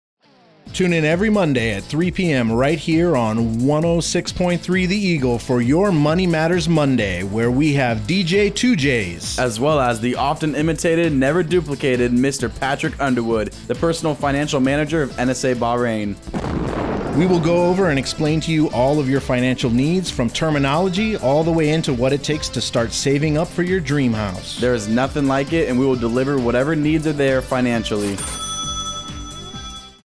Radio SpotAudio SpotMWR BahrainAFN Bahrain